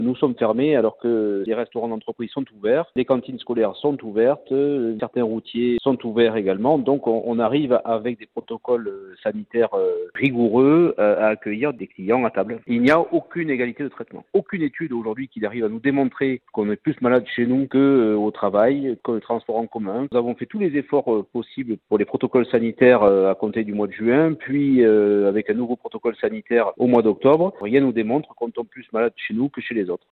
Reportage à Marseille